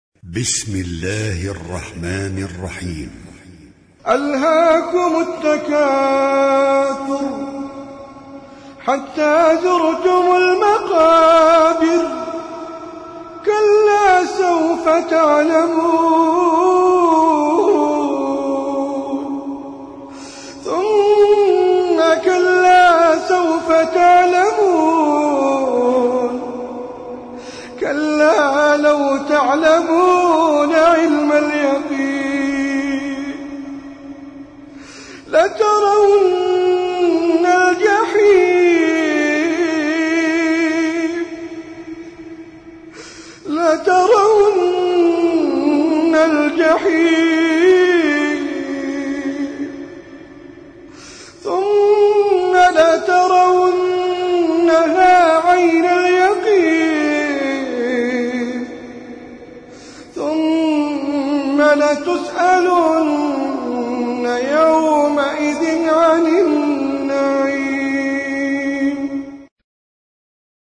Al-Mus'haf Al-Murattal (Narrated by Hafs from 'Aasem)
high quality